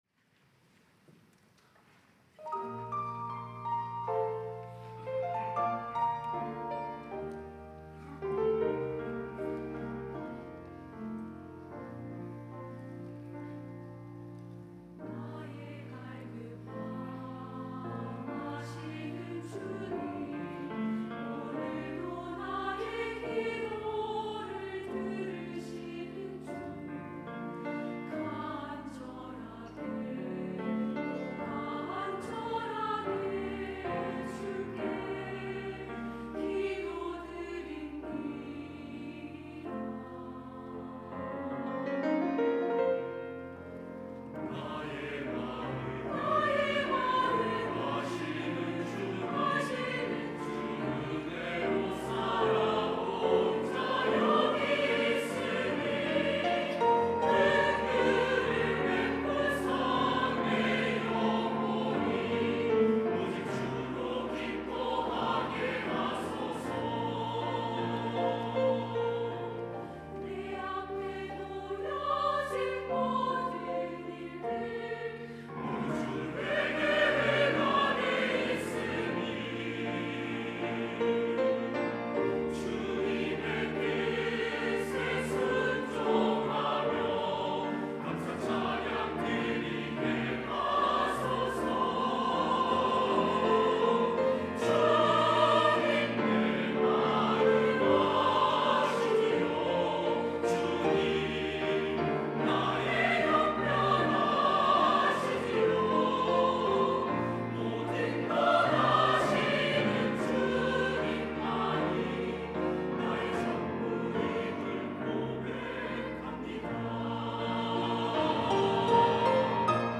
할렐루야(주일2부) - 주님만이 나의 전부입니다
찬양대